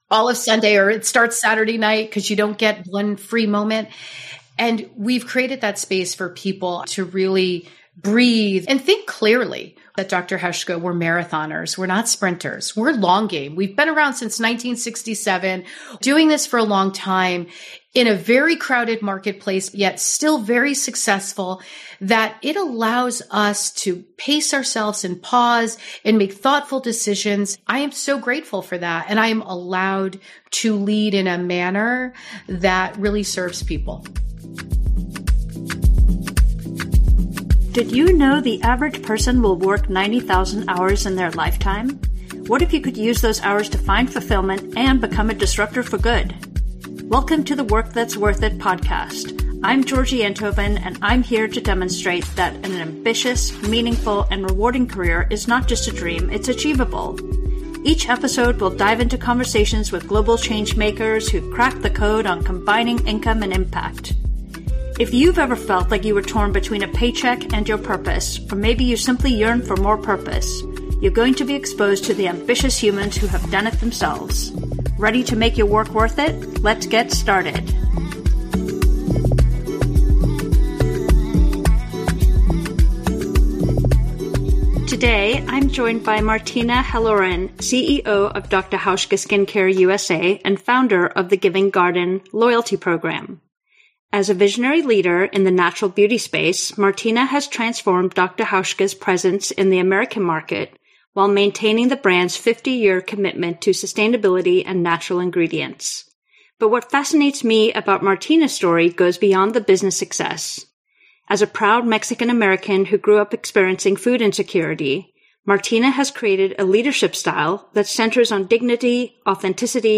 In this moving conversation